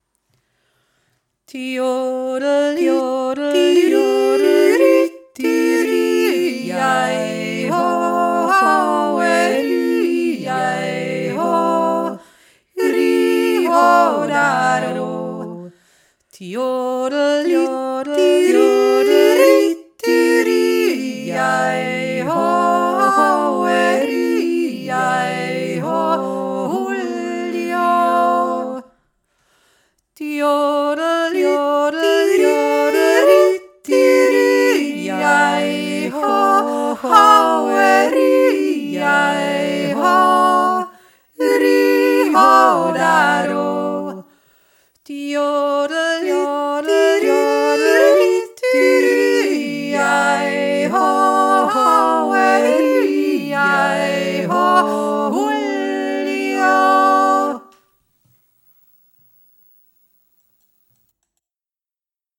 Alle Stimmen